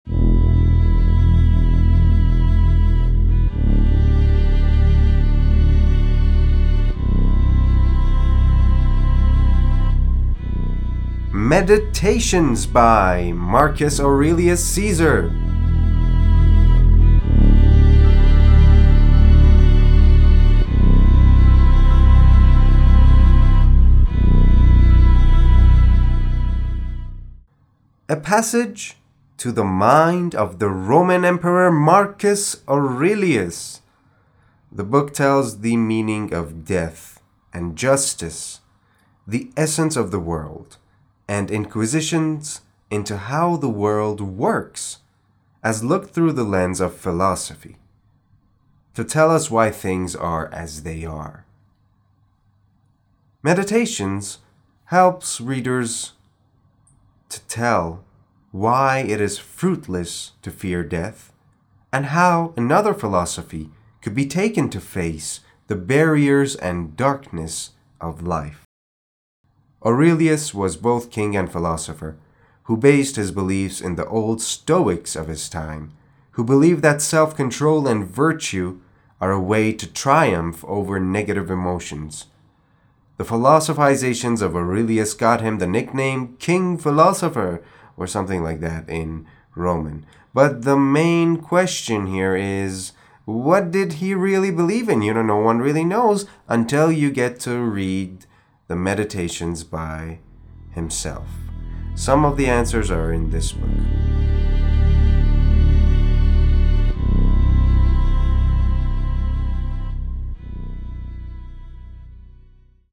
معرفی صوتی کتاب Meditations